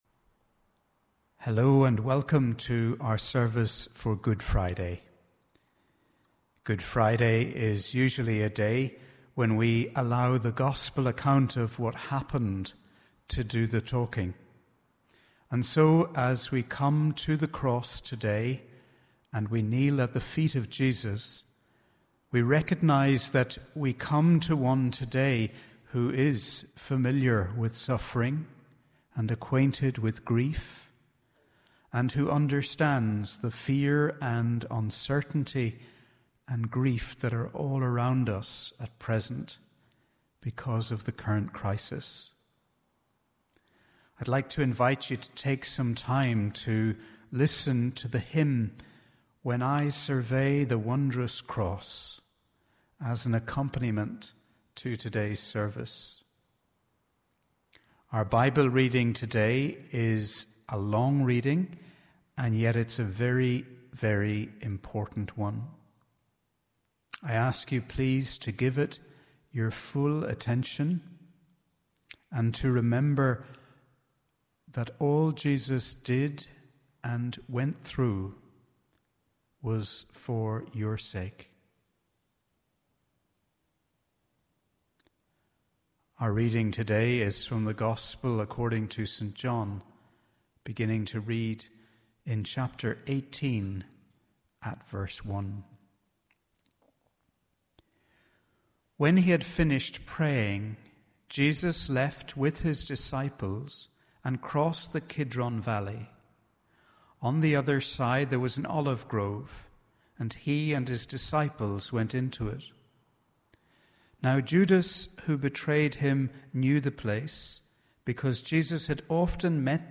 Holy-Week-Service_Friday_FINAL.mp3